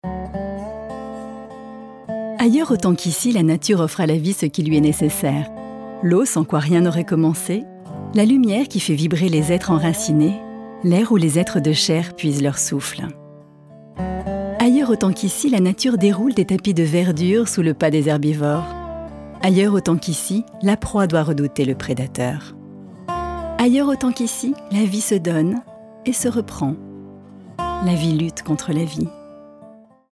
VOIX OFF documentaire